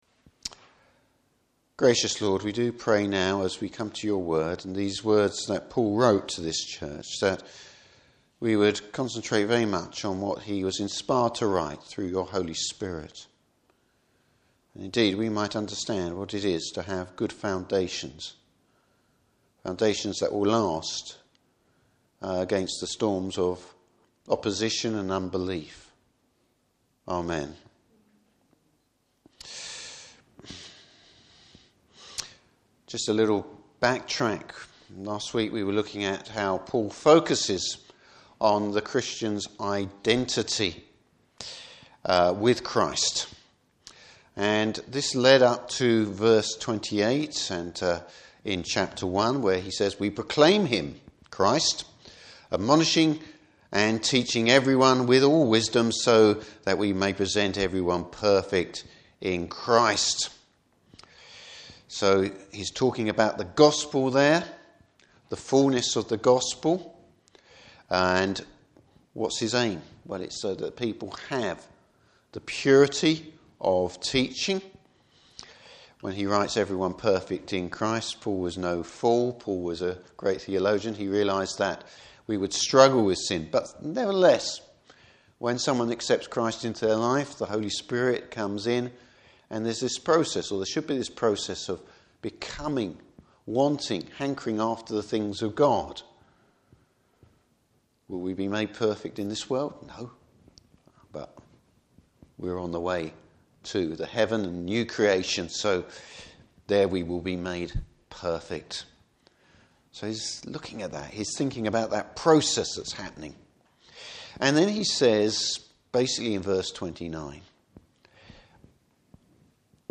Service Type: Morning Service Putting down good foundations.